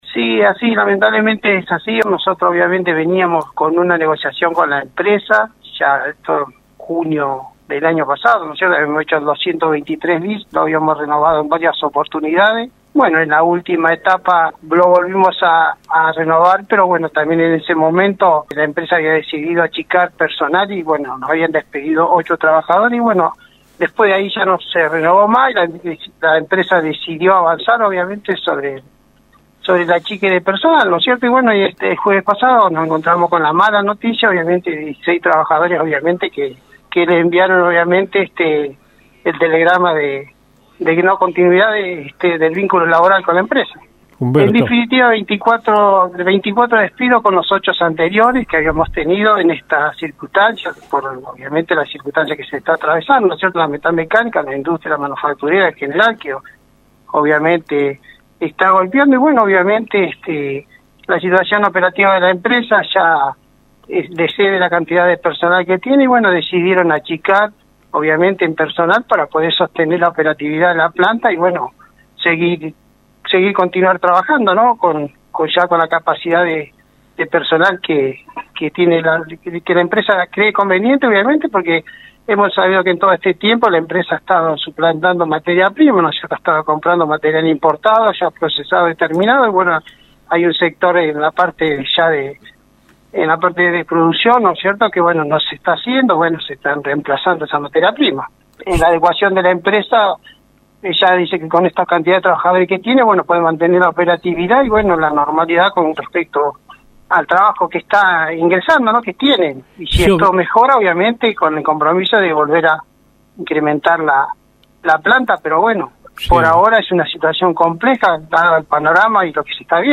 en Radio Universo